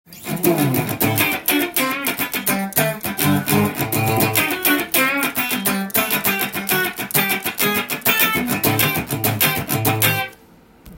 タップスイッチを使ってカッティングしてみました。
出力が減ってシングルコイルのようなシャキシャキした音になります。